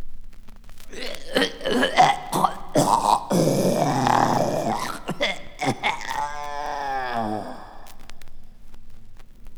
• strangulation effect - male.wav
strangulation_effect_-_male_AoB.wav